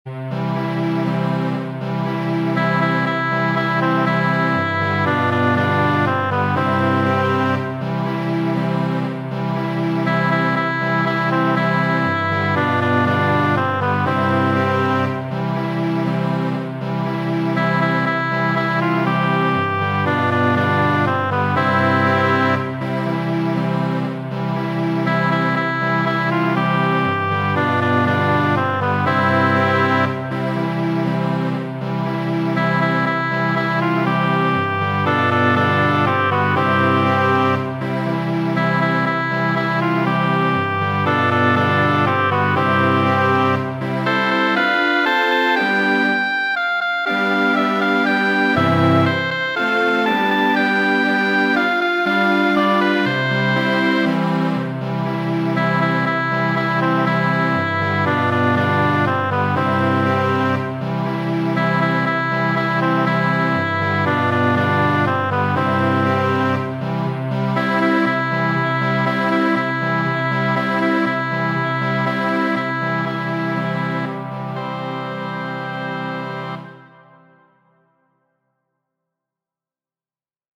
Mi promenas , muzika trankviligo